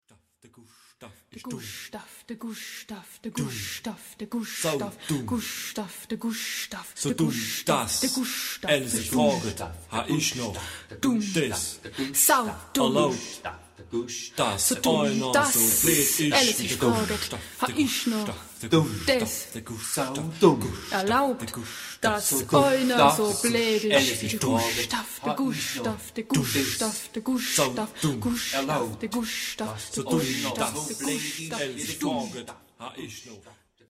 ... Rhythmus-Kanon ...
Genre-Style-Forme : Canon ; Rap ; Profane
Type de choeur :  (3 voix égales )